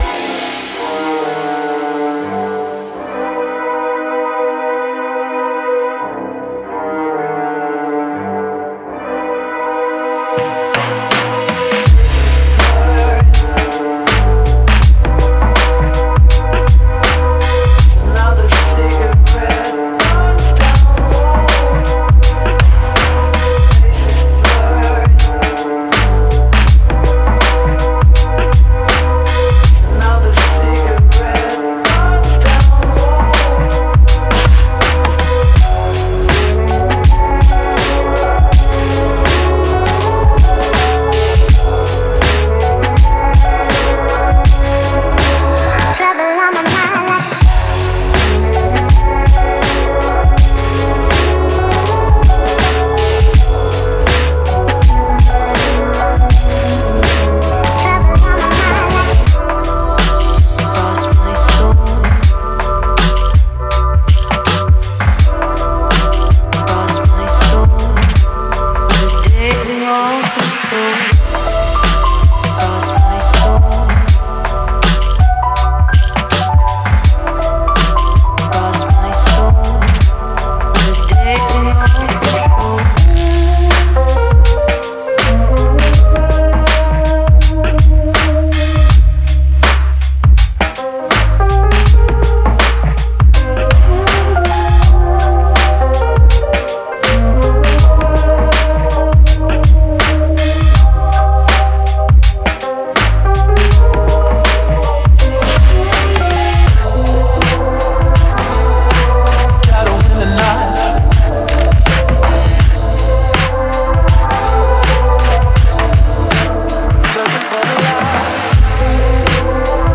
Genre:Lo-Fi Hip Hop
デモサウンドはコチラ↓